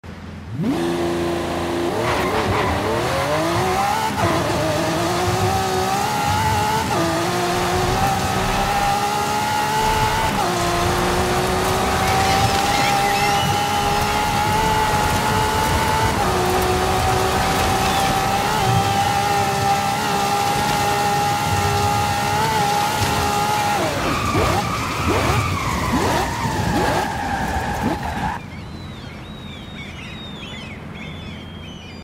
2012 Lamborghini Aventador LP700 4 Off Road sound effects free download
2012 Lamborghini Aventador LP700-4 Off-Road Launch Control - Forza Horizon 5